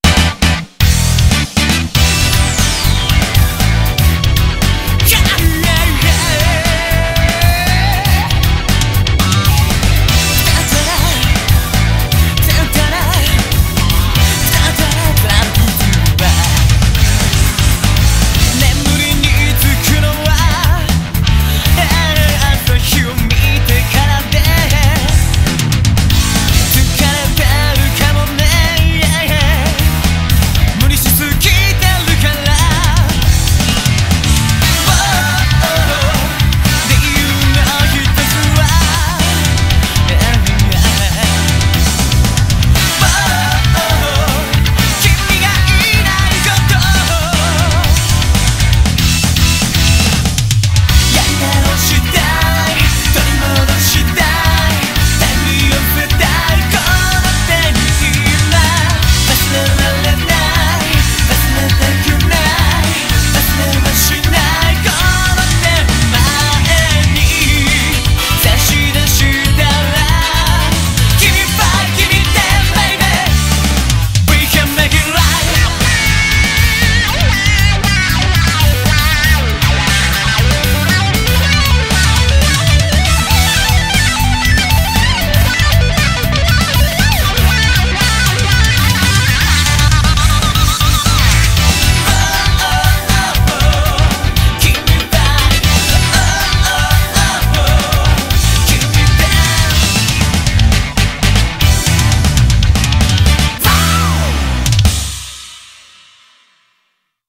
BPM118
rock songs